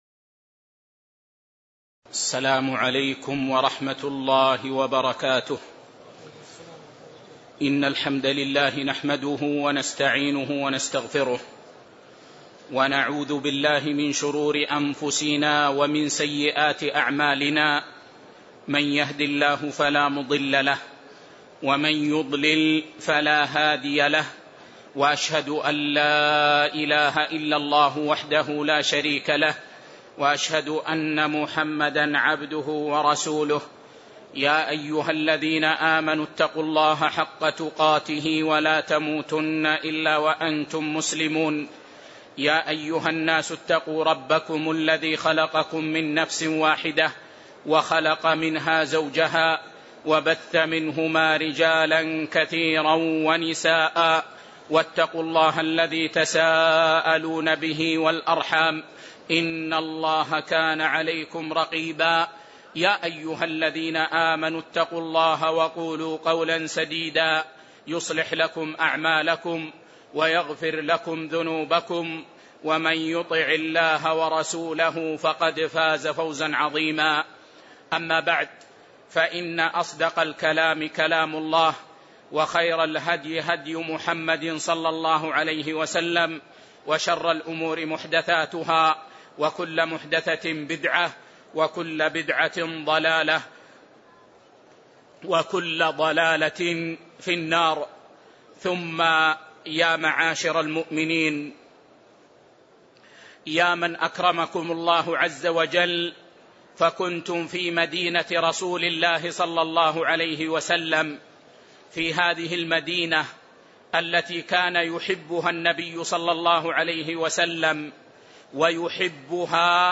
تاريخ النشر ٧ ربيع الأول ١٤٣٧ هـ المكان: المسجد النبوي الشيخ